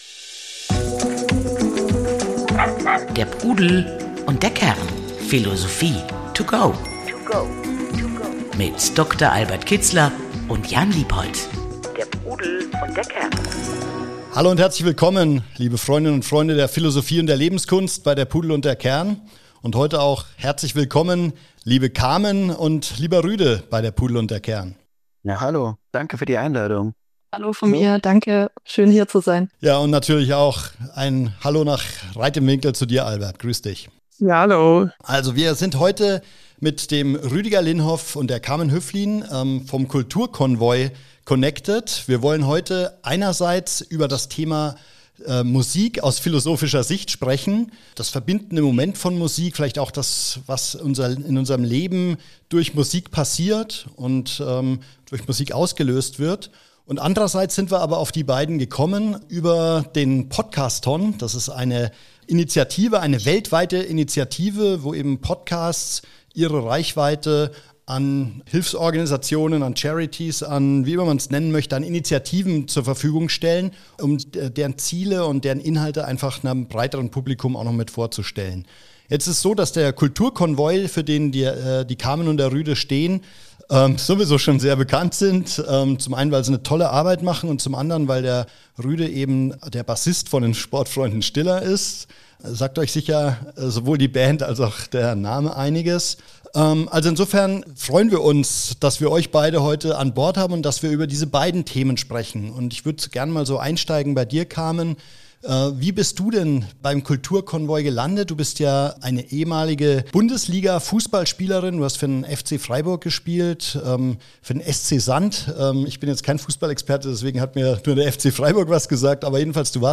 Im Gespräch zeigt sich, dass Engagement nicht nur Hilfe für andere bedeutet, sondern auch dem eigenen Leben Sinn und Richtung geben kann.